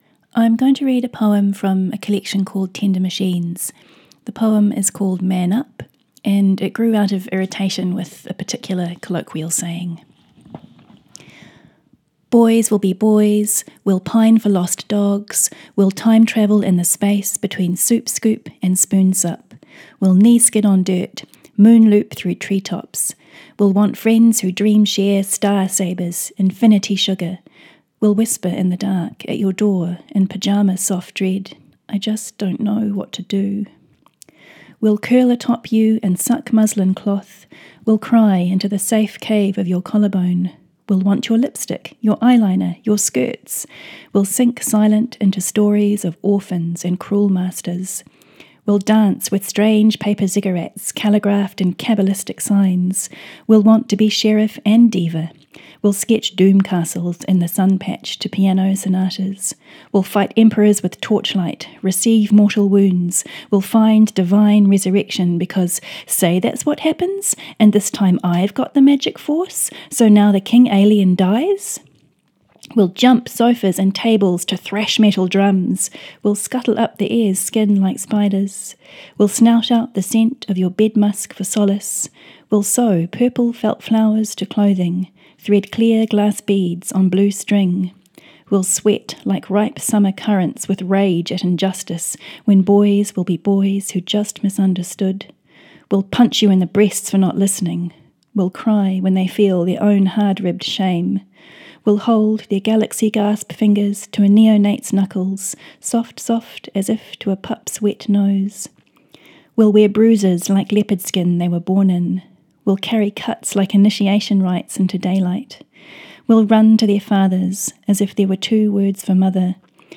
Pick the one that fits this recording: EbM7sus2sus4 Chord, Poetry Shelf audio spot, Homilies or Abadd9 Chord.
Poetry Shelf audio spot